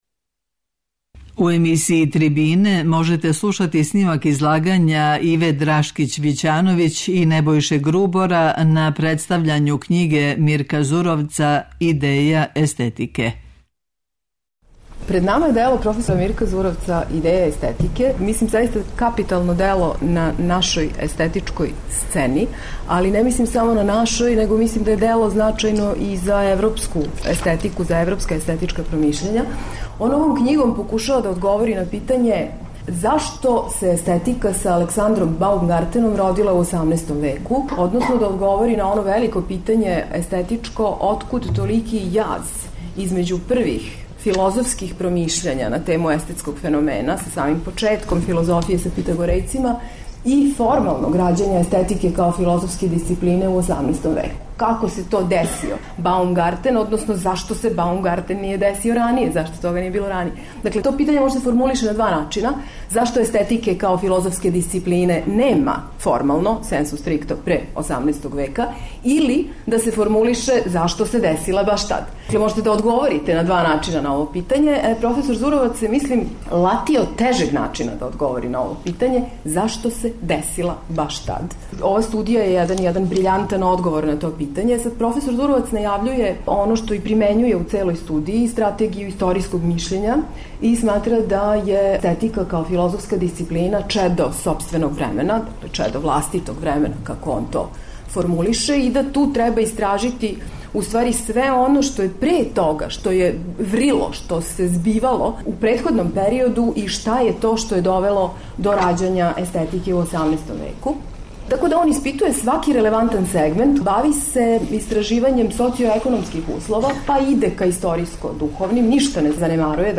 Снимак је забележен 21. децембра 2016. у Народној библиотеци Србије у Београду.